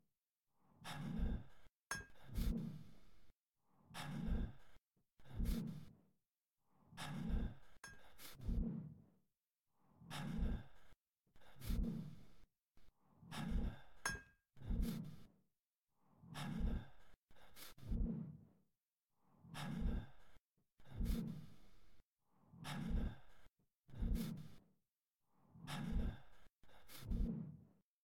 intenseworkout
breath breathe cables clang gym machine metal pulleys sound effect free sound royalty free Memes